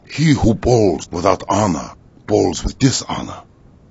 gutterball-3/Gutterball 3/Commentators/Master/zen_hewhobowlswithouthonor.wav at 58b02fa2507e2148bfc533fad7df1f1630ef9d9b